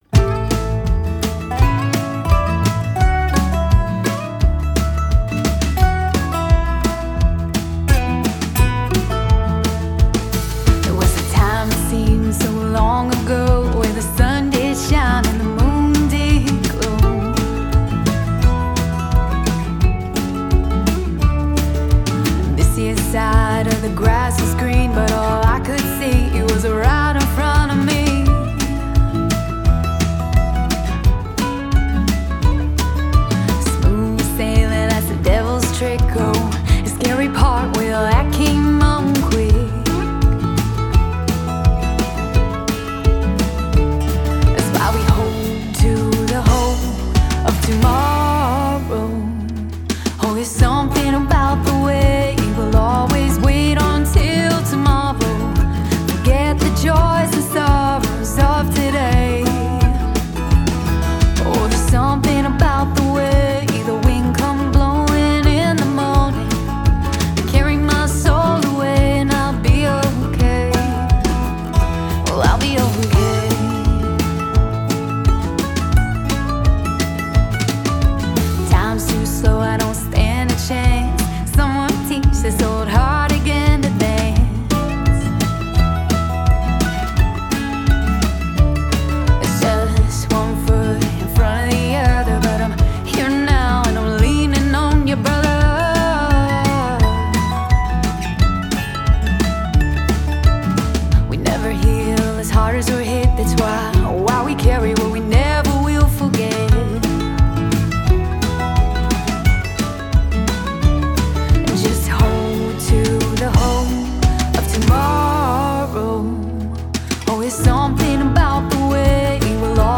an Americana/folk/rock band from Tampa Bay
expressive lead vocals
and the band’s music tracks are well played.